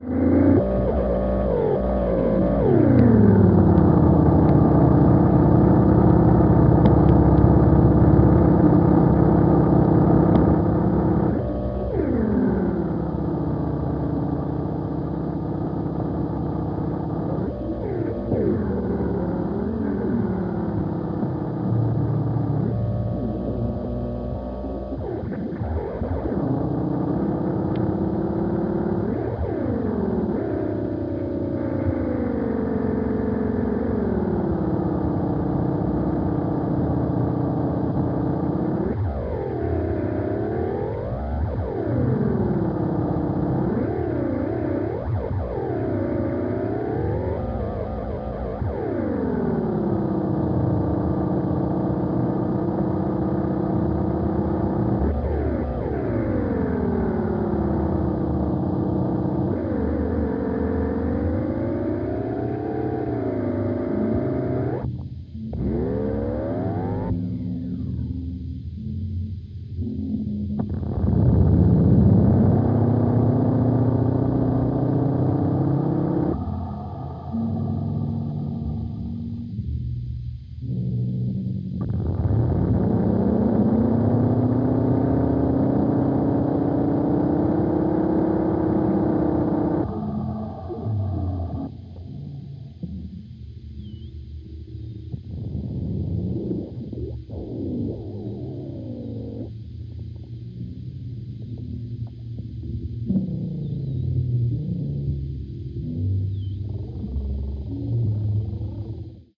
tape loops in contrapuntal collision.